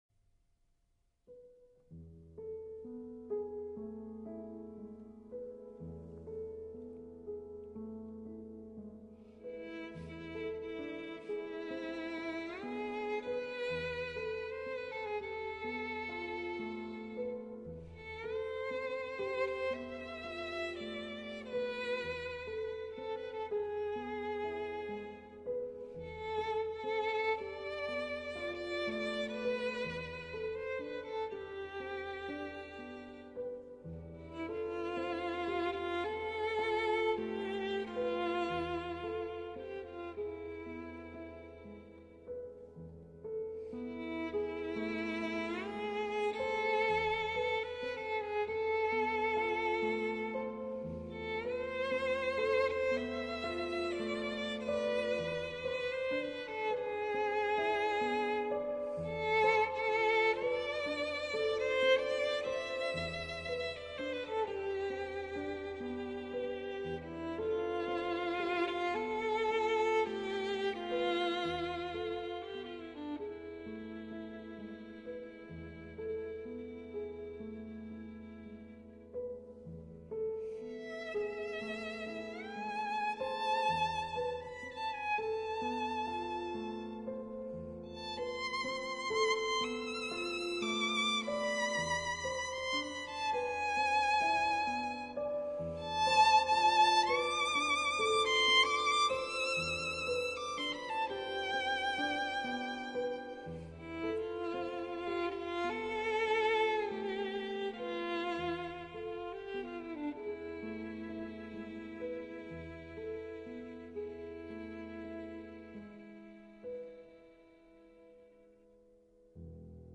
佛音 冥想 佛教音乐 返回列表 上一篇： 一声佛号一声心(纯音乐